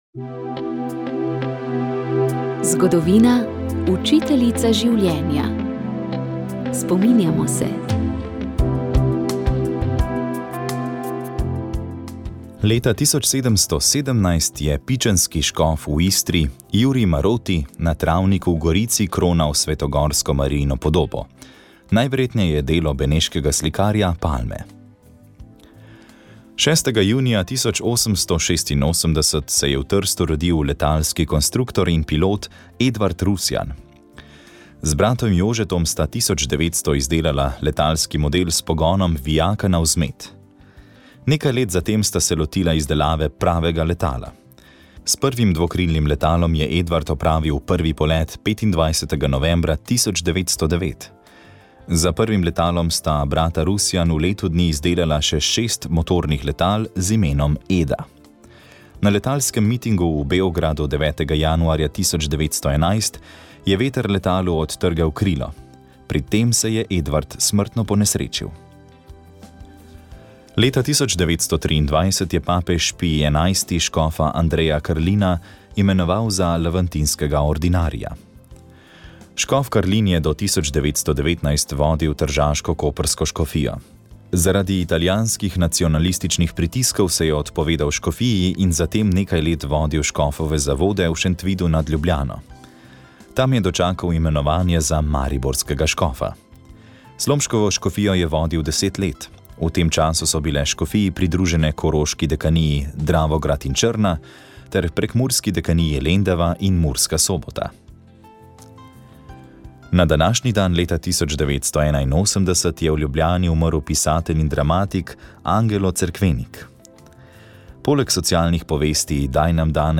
Prisluhnite pogovoru o slikarju in monografiji!